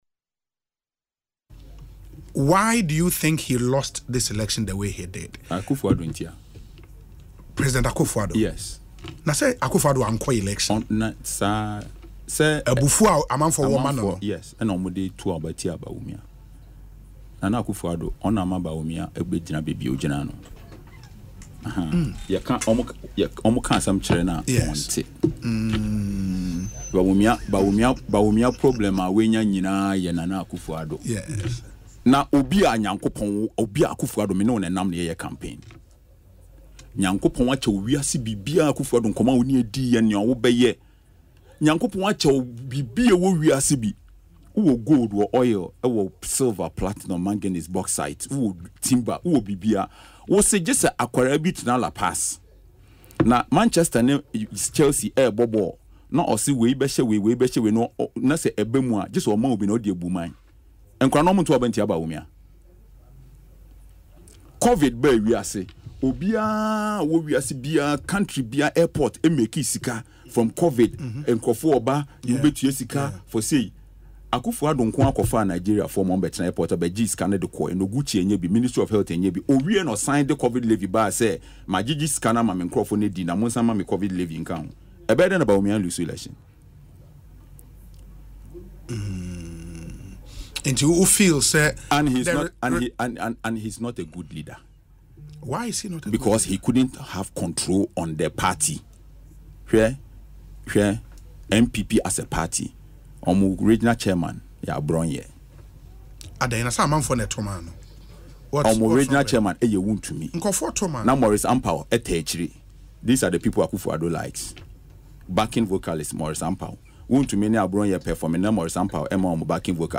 The newly elected MP for Gomoa Central in an interview on Asempa FM’s Ekosii Sen without mincing words declared President Akufo-Addo the caused the humiliating defeat.